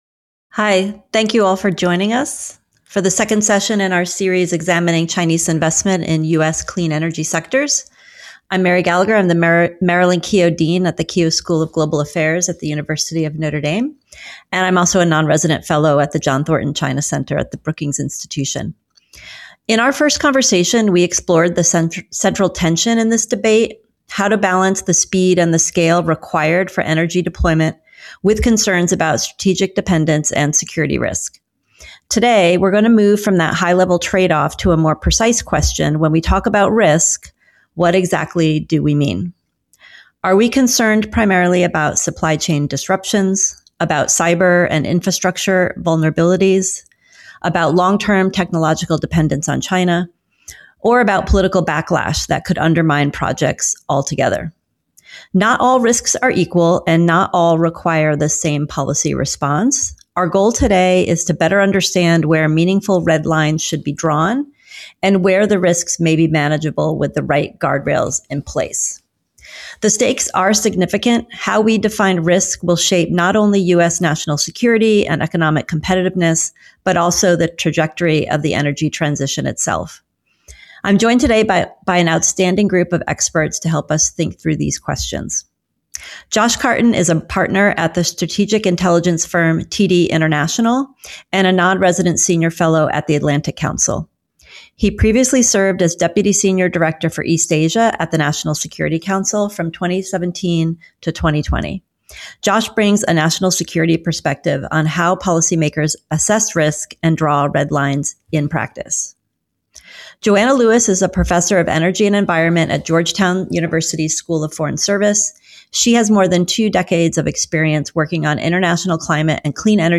Experts discuss where meaningful red lines should be drawn around Chinese investment in U.S. clean energy sectors.